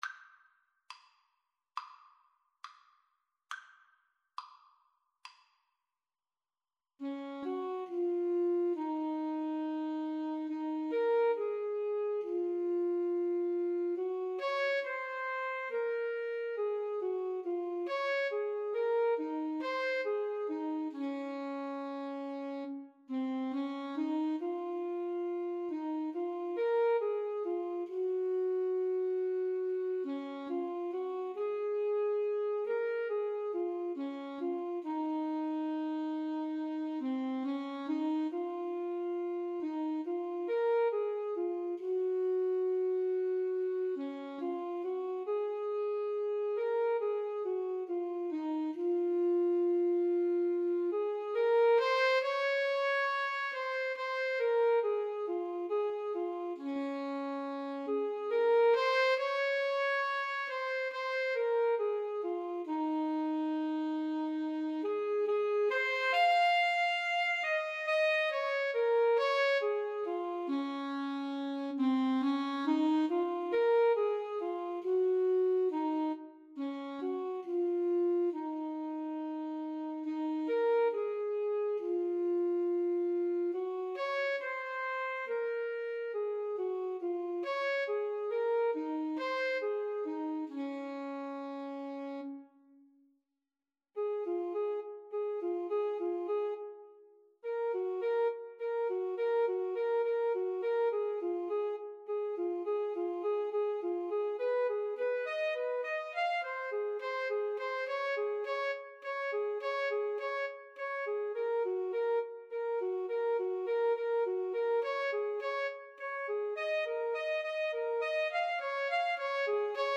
4/4 (View more 4/4 Music)
Espressivo = c. 69
Classical (View more Classical Alto Saxophone Duet Music)